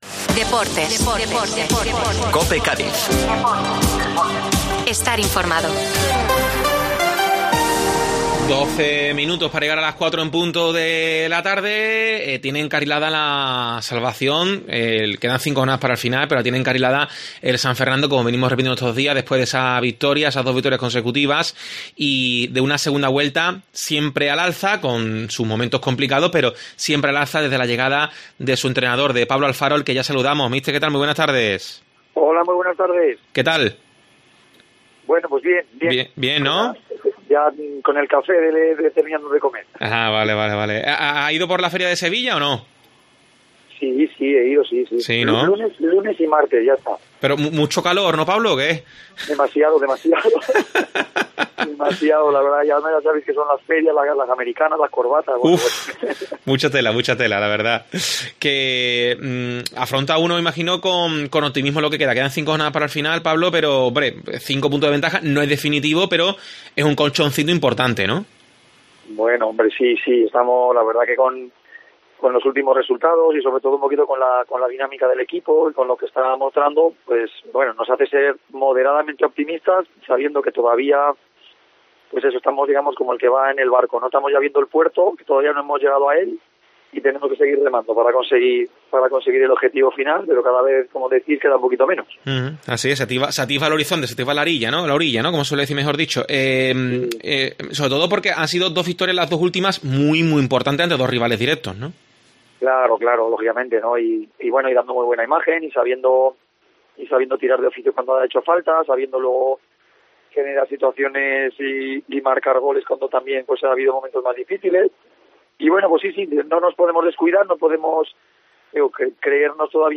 ESCUCHA LA ENTREVISTA A PABLO ALFARO EN DEPORTES COPE CÁDIZ